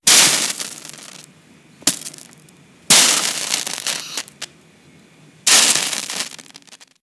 Gotas de agua cayendo sobre una plancha caliente
vapor
Sonidos: Agua
Sonidos: Hogar